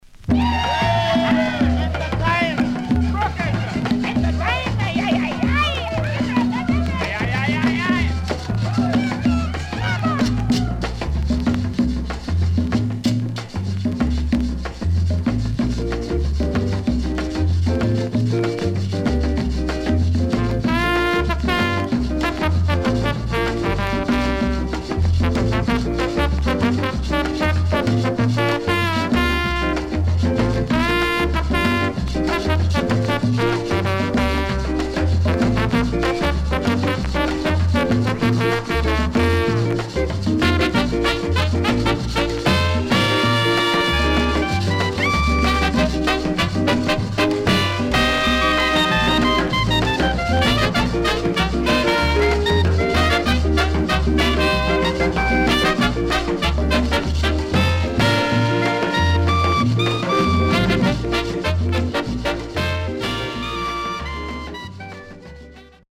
Rare.W-Side Caribbean Mood Inst
SIDE A:序盤サッというノイズありますが落ち着きます。